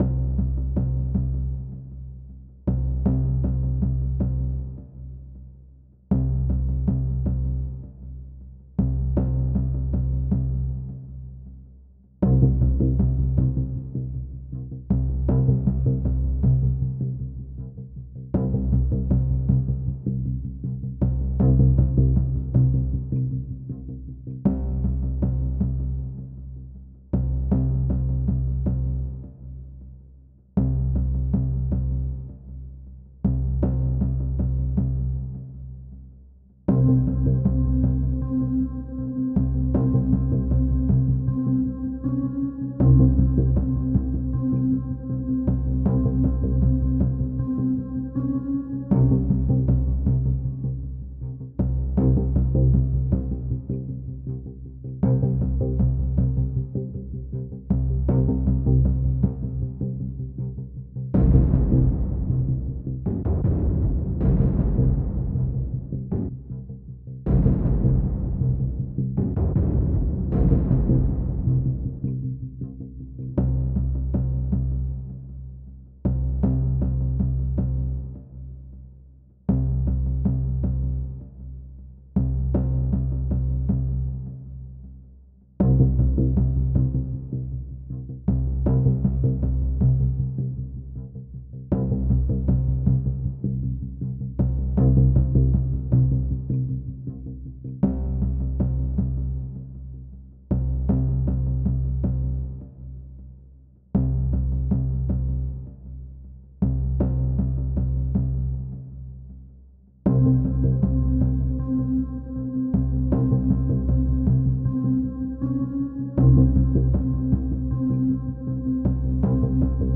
tension driving dark ambient